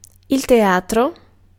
Ääntäminen
Synonyymit arène Ääntäminen France (Paris): IPA: [lə te.atʁ] Tuntematon aksentti: IPA: /te.atʁ/ IPA: /te.ɑtʁ/ Haettu sana löytyi näillä lähdekielillä: ranska Käännös Ääninäyte Substantiivit 1. dramma {f} 2. teatro {m} Suku: m .